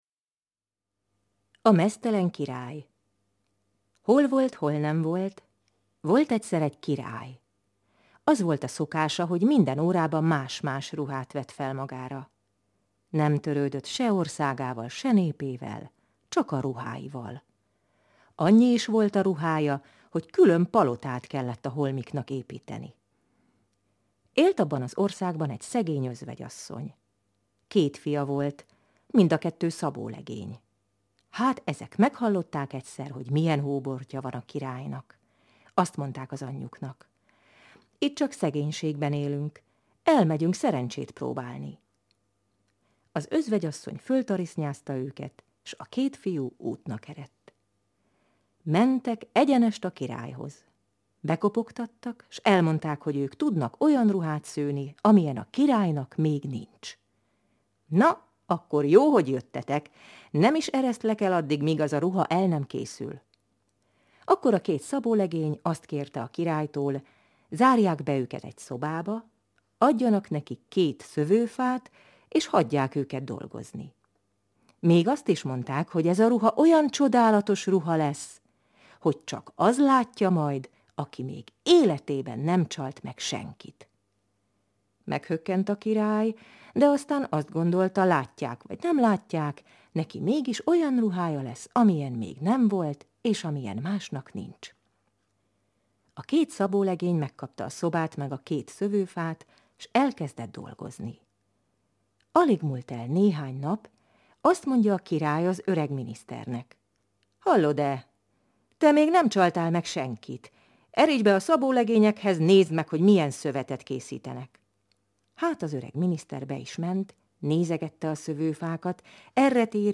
Hangos mesék